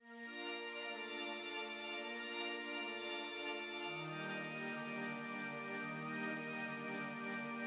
IBI Chimey Riff Bb-Eb.wav